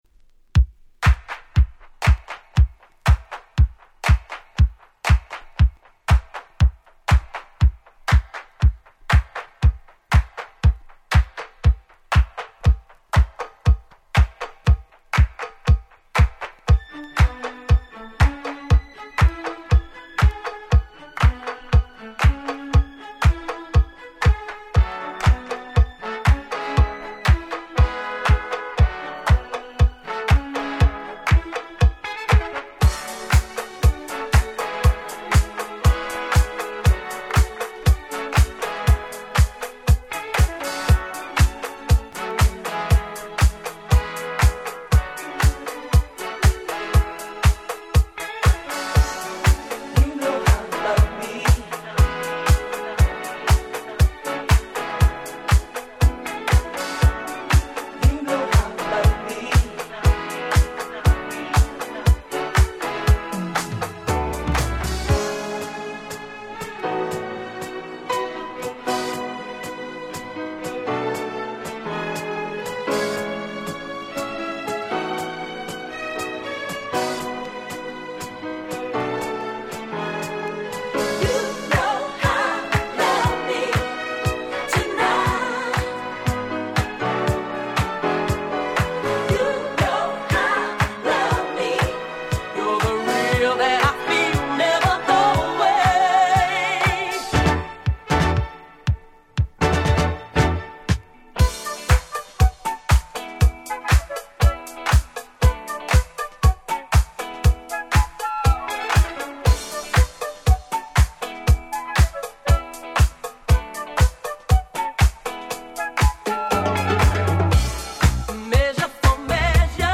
もう展開が神的にドラマティック！！